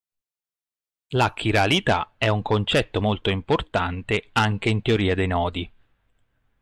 Hyphenated as te‧o‧rì‧a Pronounced as (IPA) /te.oˈri.a/